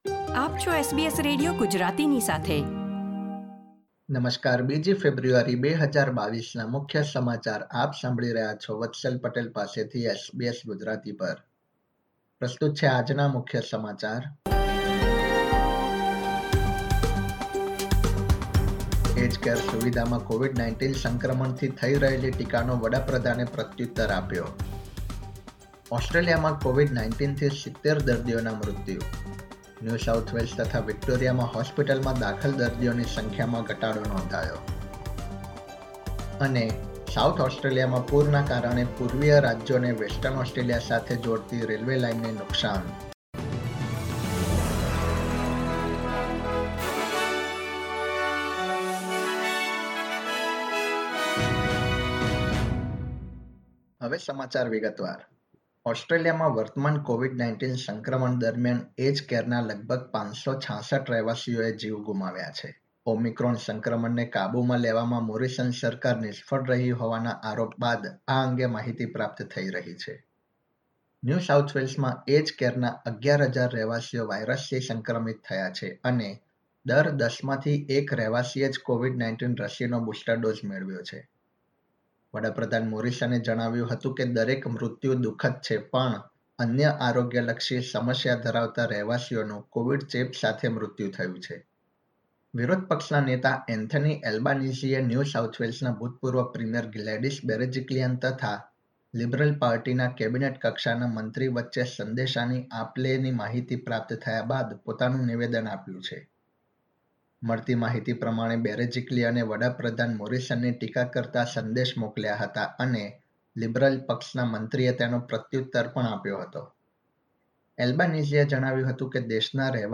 SBS Gujarati News Bulletin 2 February 2022